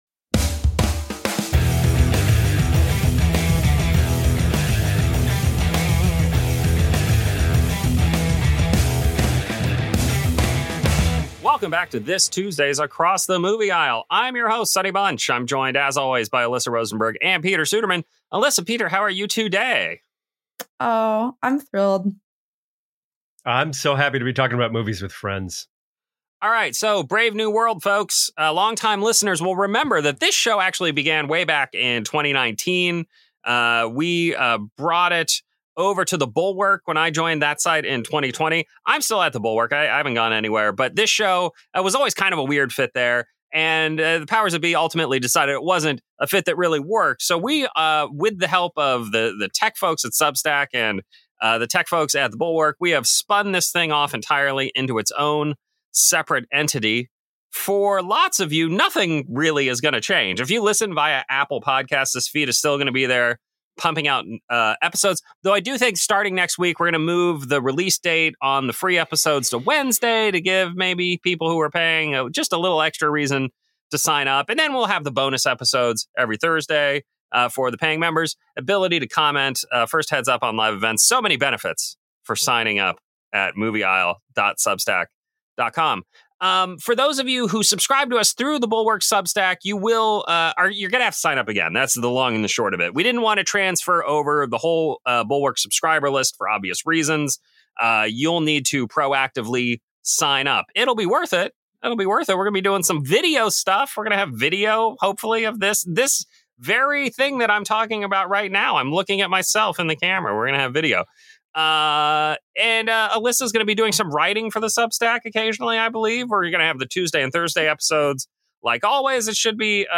Playing with Audition’s presets and the such.